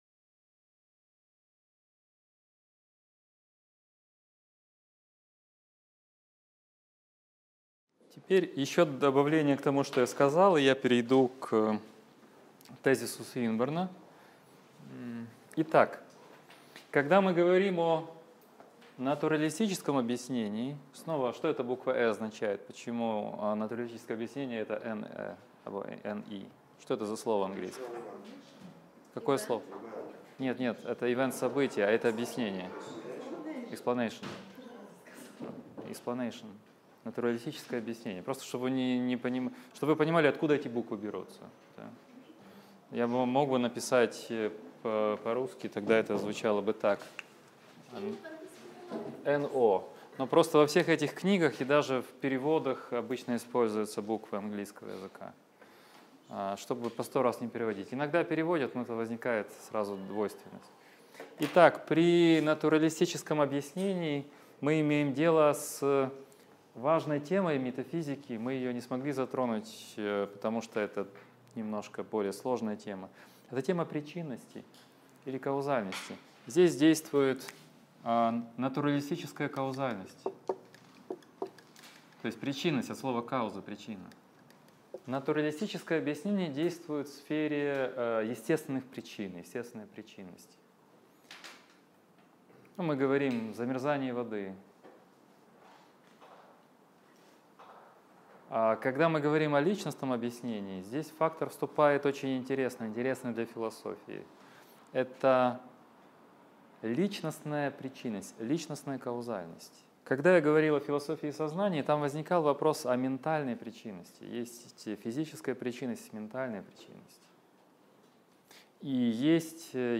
Аудиокнига Лекция 17. Аргументы в пользу бытия Бога: Суинберн и Платинга | Библиотека аудиокниг